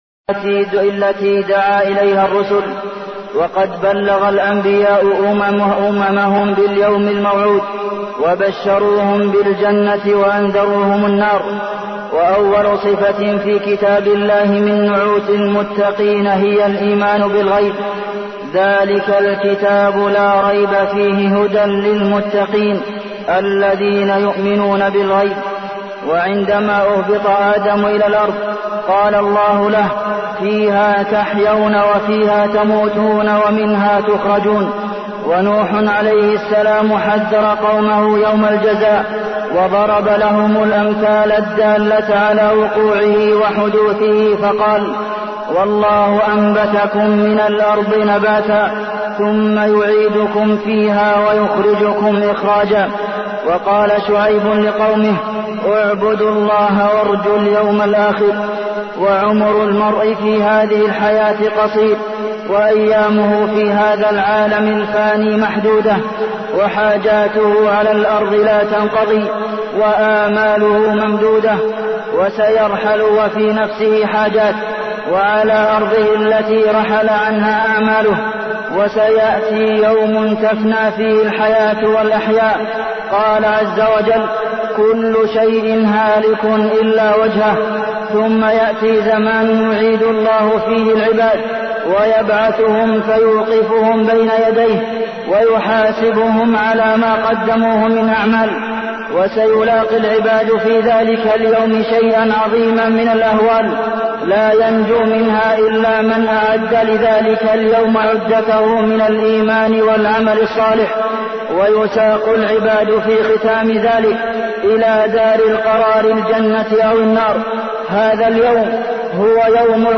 تاريخ النشر ٢١ محرم ١٤٢٠ هـ المكان: المسجد النبوي الشيخ: فضيلة الشيخ د. عبدالمحسن بن محمد القاسم فضيلة الشيخ د. عبدالمحسن بن محمد القاسم اليوم الآخر